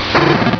pokeemerald / sound / direct_sound_samples / cries / swinub.aif